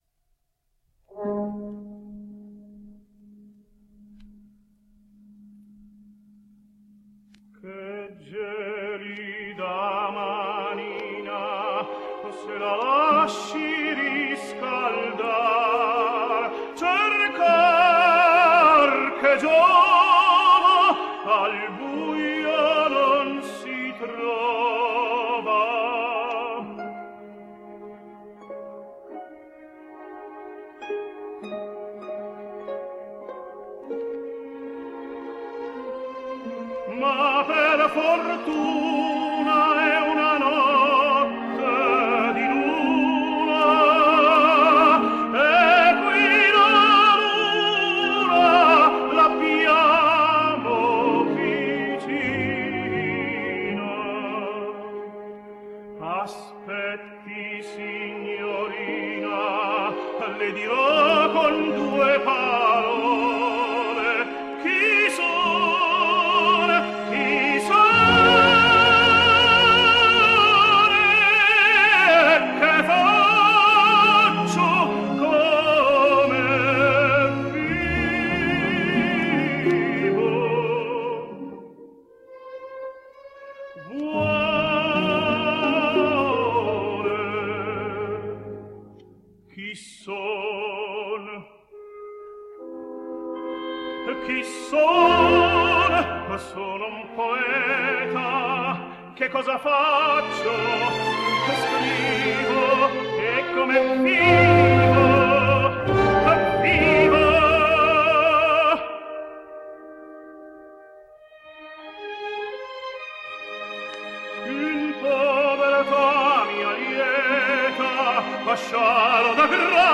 Italian Tenor.
and from the verismo period, Puccini’s La Boheme.